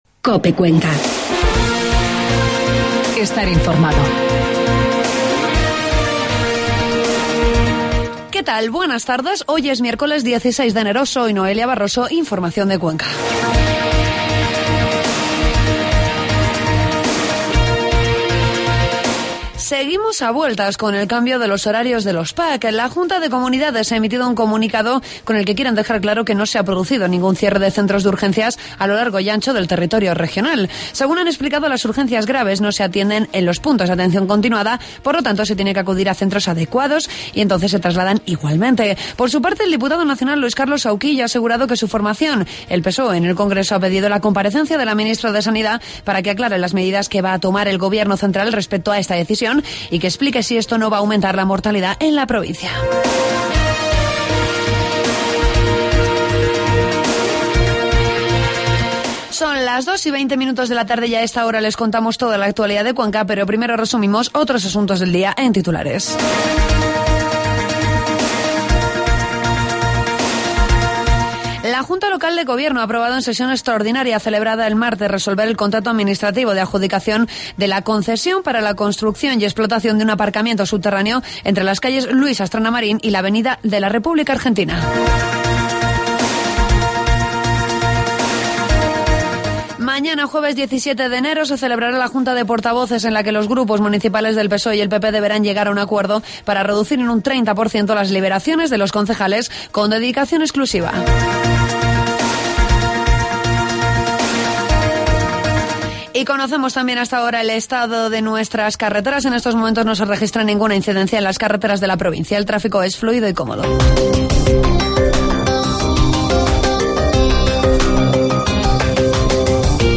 AUDIO: Toda la información de la provincia de Cuenca en los informativos de mediodía de COPE